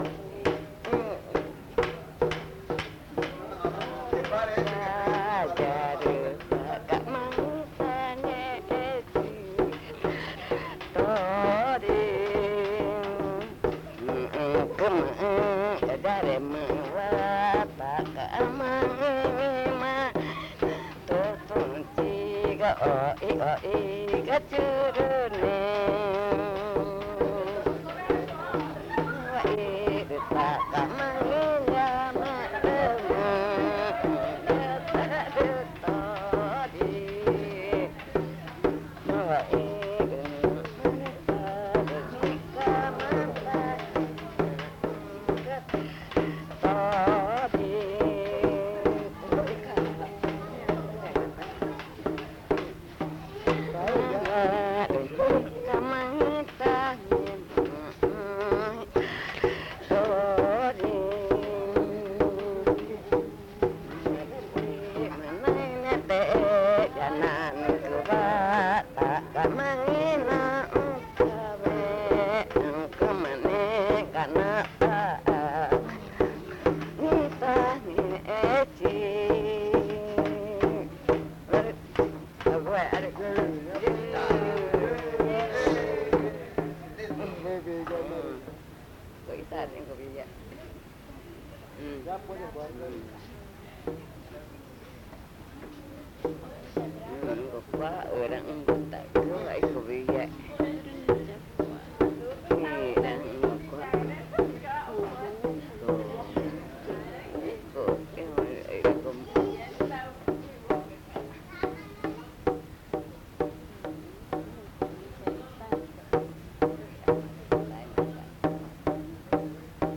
Canto sobre el clan Cascabel y los consejos de los padres
El Vergel, Amazonas (Colombia)
Canta con tristeza en el alma porque sus padres fallecieron, pero al mismo tiempo reconoce que le dejaron una enseñanza importante.
She sings with sadness in her heart because her parents have passed away, but at the same time she acknowledges that they left her an important teaching.